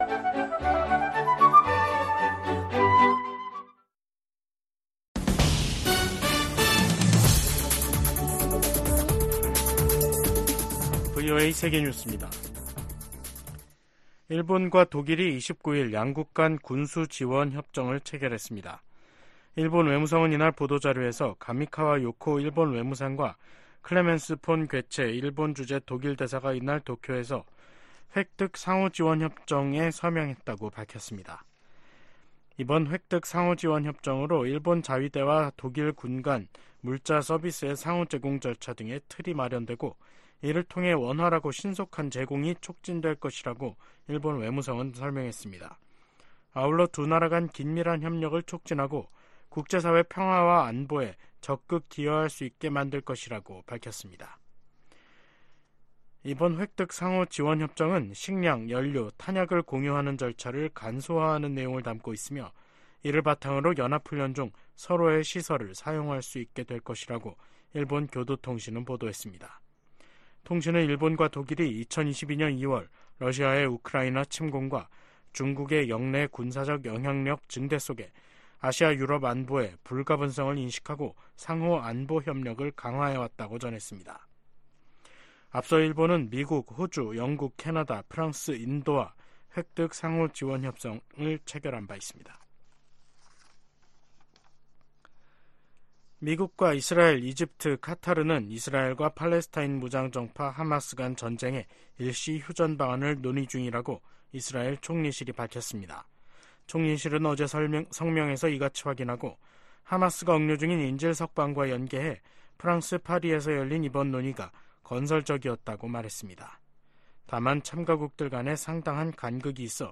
VOA 한국어 간판 뉴스 프로그램 '뉴스 투데이', 2024년 1월 29일 3부 방송입니다. 북한은 어제 시험발사한 미사일이 새로 개발한 잠수함발사 순항미사일이라고 밝혔습니다. 제이크 설리번 미국 국가안보보좌관이 왕이 중국 외교부장에게 북한의 무기실험과 북러 협력에 대한 우려를 제기했다고 미국 정부 고위당국자가 밝혔습니다. 백악관은 북한의 첨단 무기 능력 추구와 관련해 동맹국 보호 의지를 재확인했습니다.